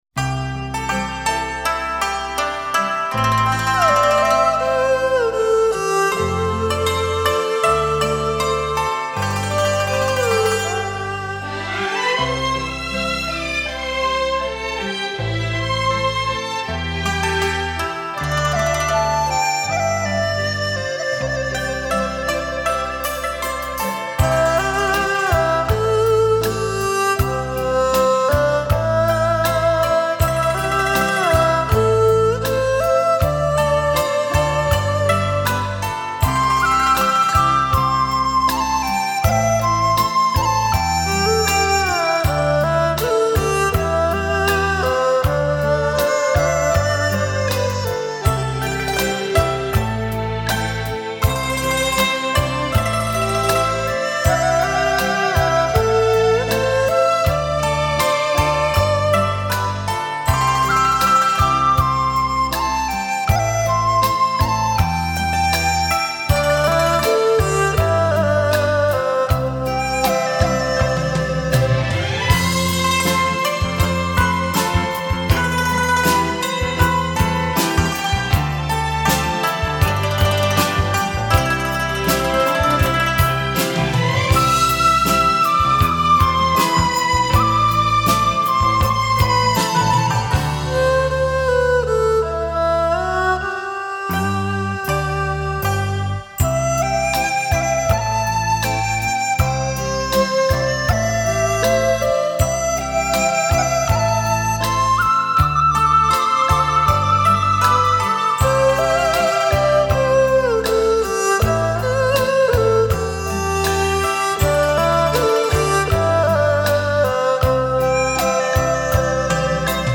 悠扬舒缓的音乐